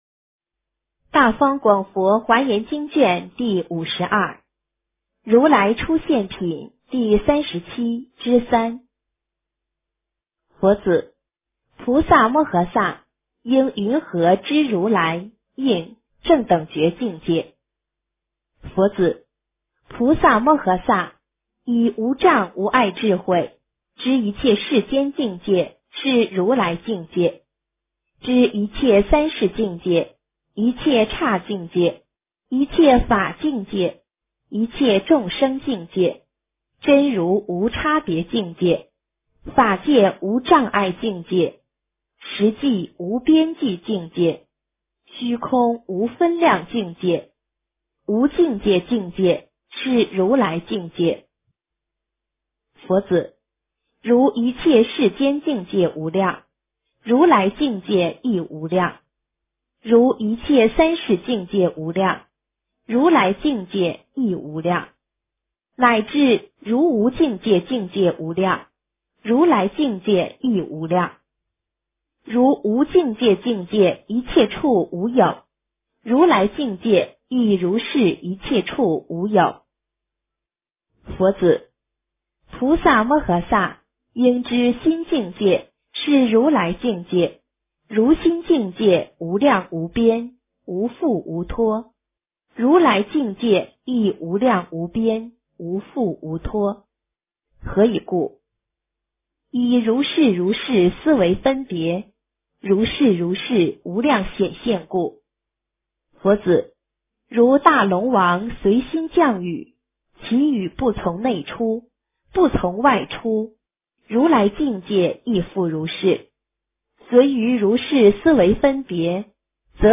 华严经52 - 诵经 - 云佛论坛